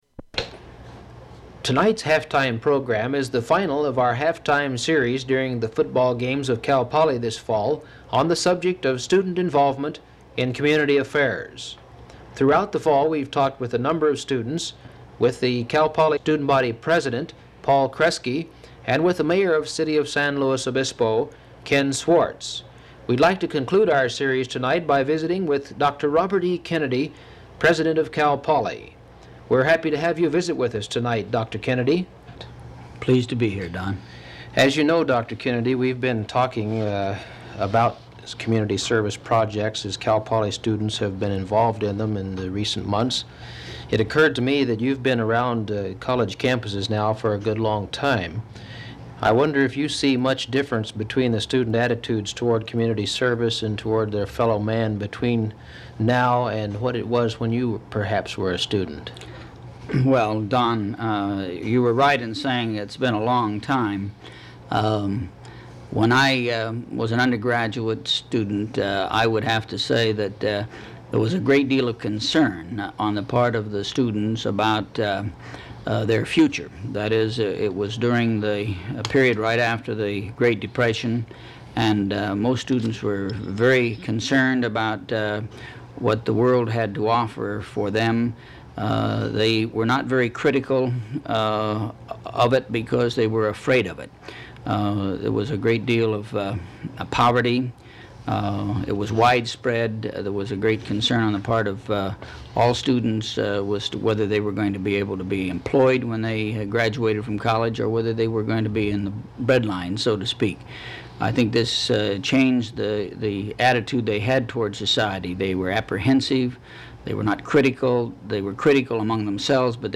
Interview
• Open reel audiotape